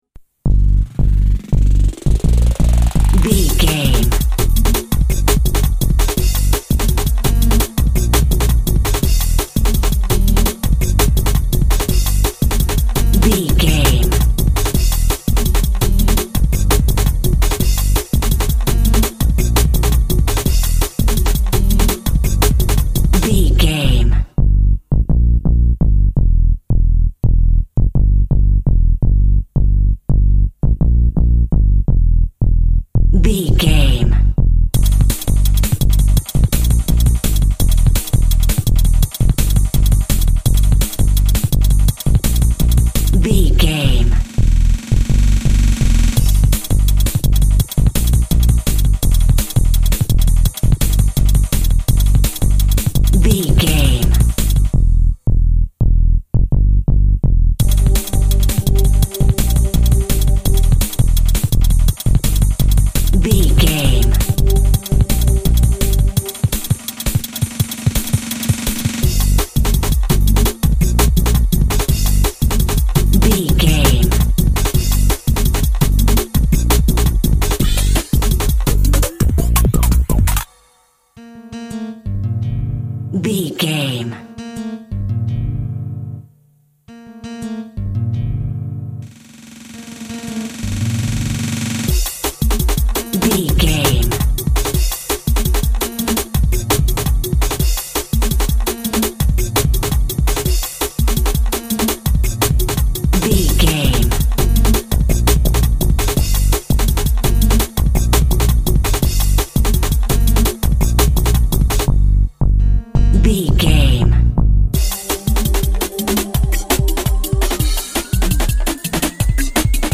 Fast paced
Aeolian/Minor
Fast
futuristic
frantic
driving
energetic
epic
groovy
synthesiser
drums
drum machine
synth lead
synth bass